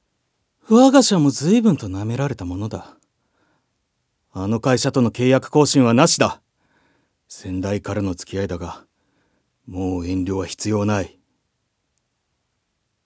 セリフ2